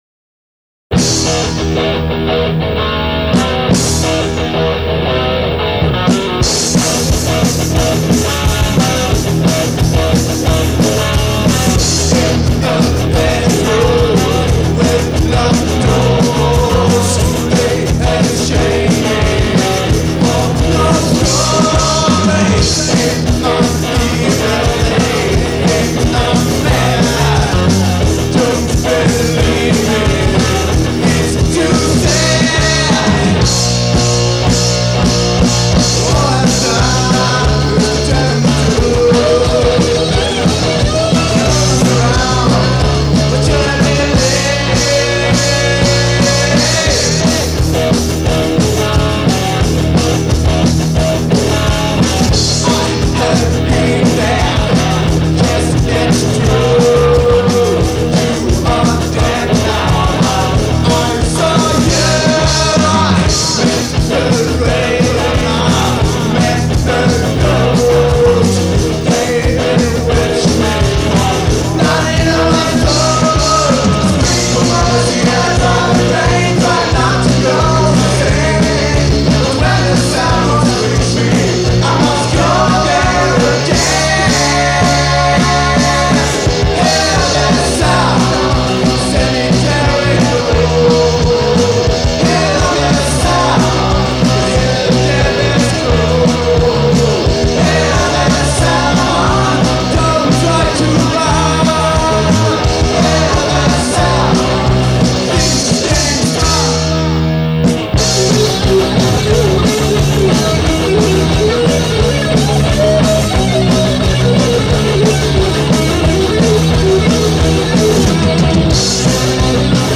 Bas 19år
Gitarr, sång 19år
Trummor 19år
Deras musik är oftas ösig men ändå polerad metall
ösiga låtar med klösande, snärtiga gitarrer
Låtarna är från olika demoinepelningar.